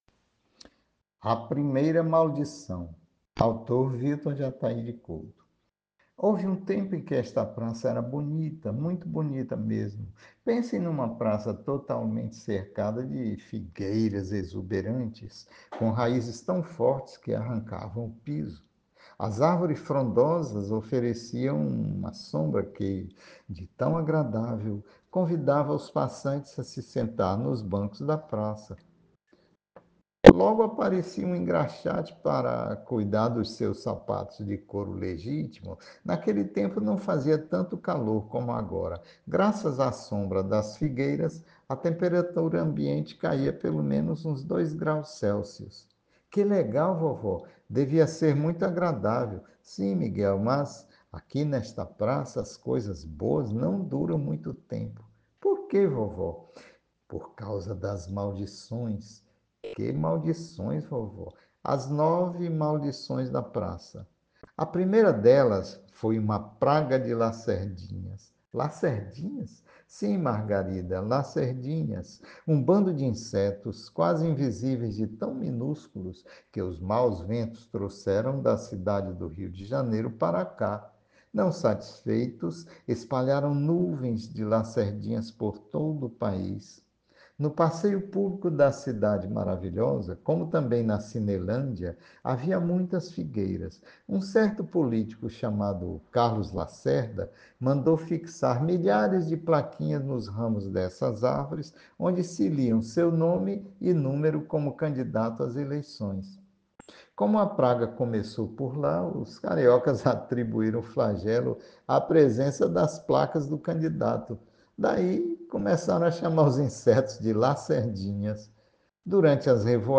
Ouça o áudio com narração do autor: